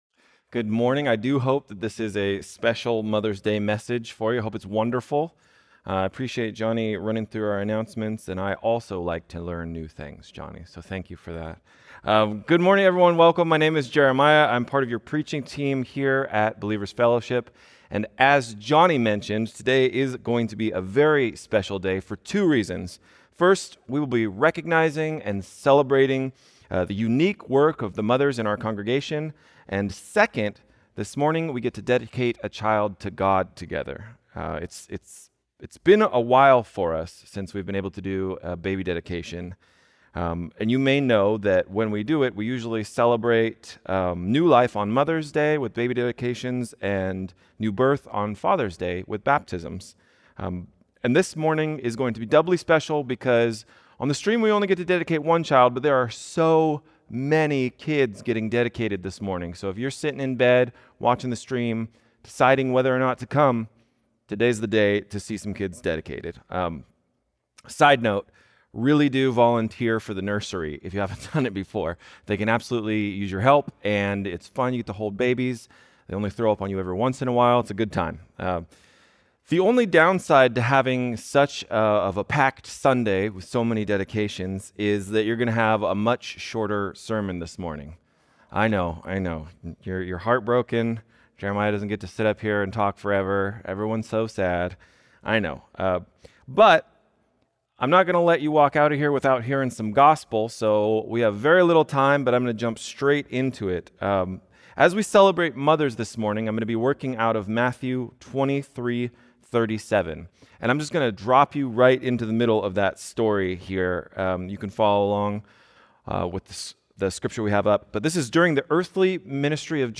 Baby Dedication and Mother’s Day